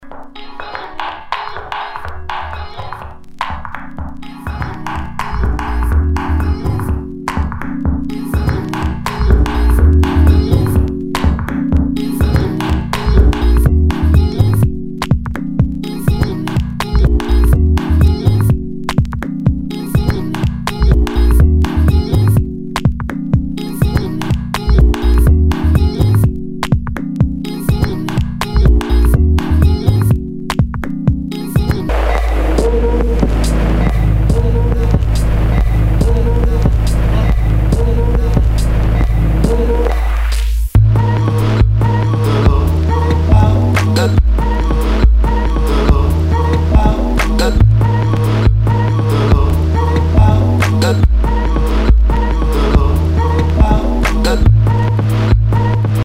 Nu- Jazz/BREAK BEATS
ナイス！エレクトロニカ/ベース！
プレイ可能ですが盤に歪みあり。（相性が悪いと音飛びするかもしれません）